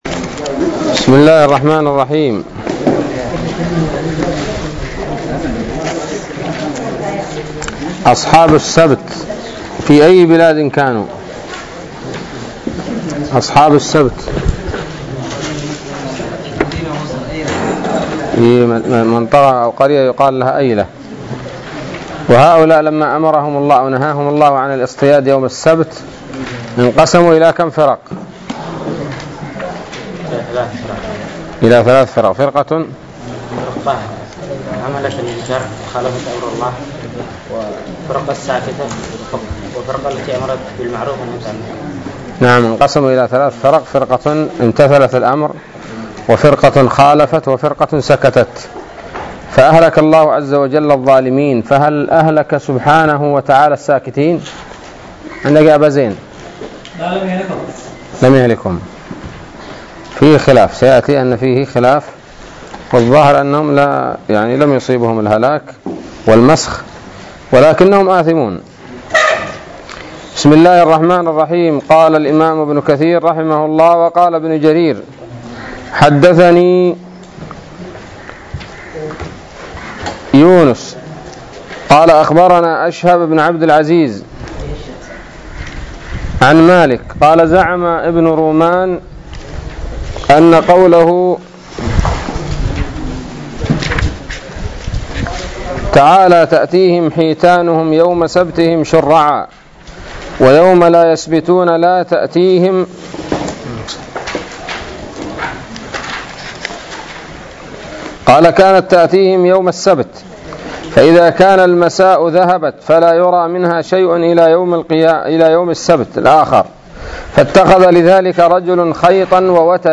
007 سورة الأعراف الدروس العلمية تفسير ابن كثير دروس التفسير